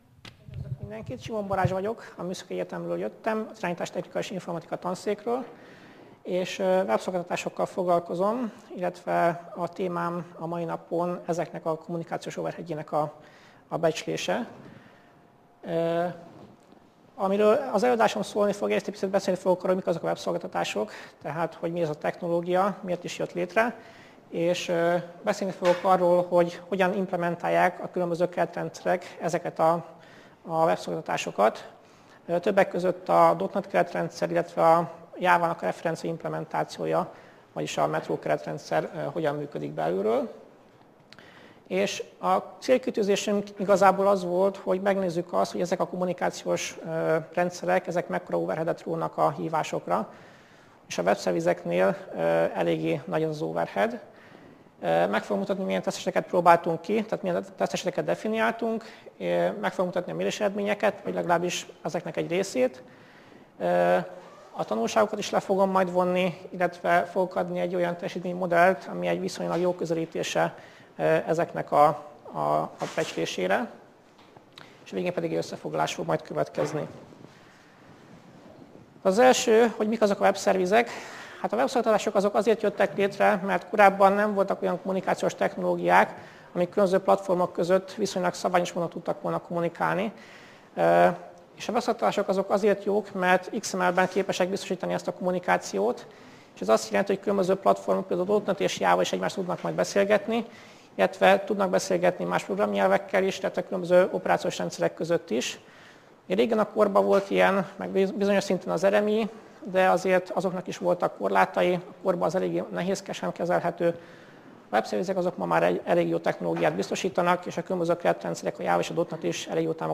Networkshop 2012 konferencia